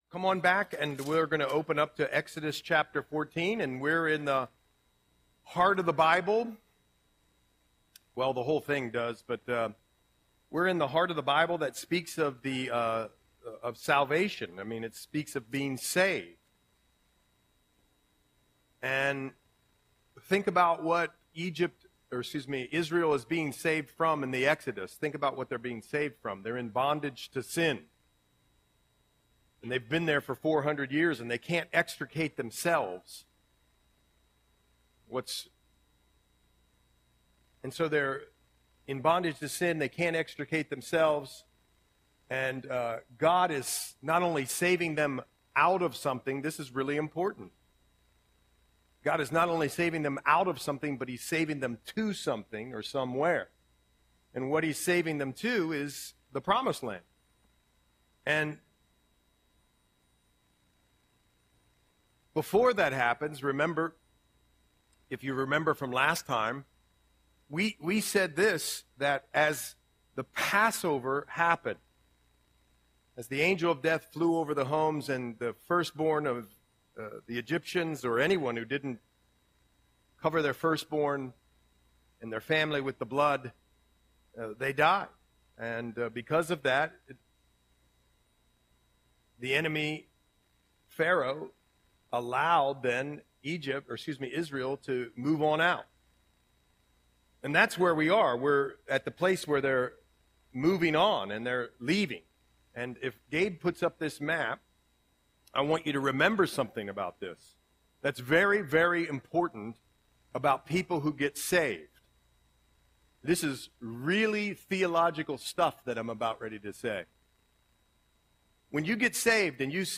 Audion Sermon - January 15, 2025